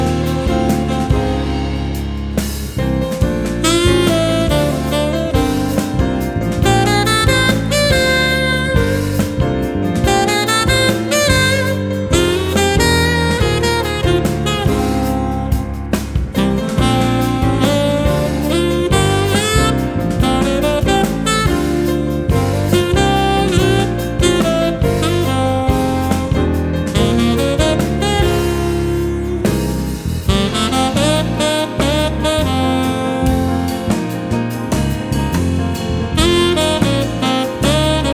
saxes
bass
drums & percussion
pianos and composer